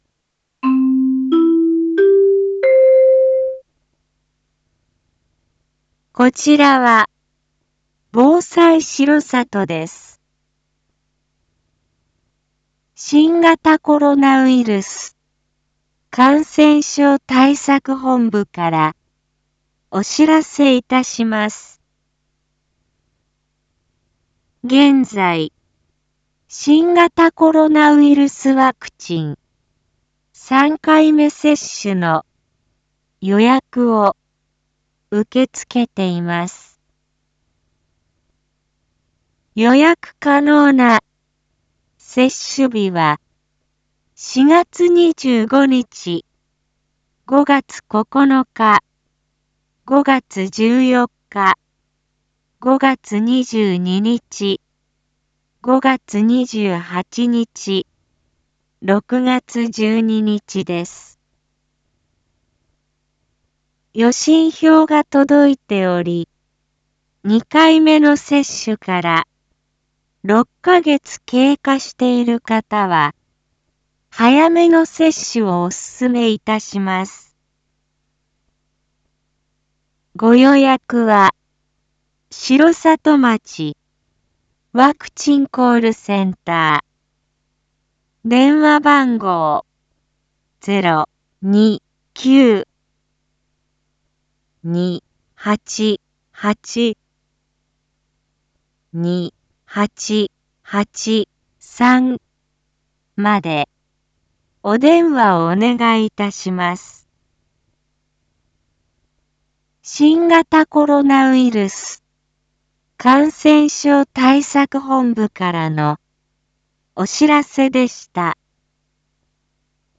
一般放送情報
Back Home 一般放送情報 音声放送 再生 一般放送情報 登録日時：2022-04-22 19:02:11 タイトル：R4.4.22 ワクチン3回目接種予約 インフォメーション：こちらは、防災しろさとです。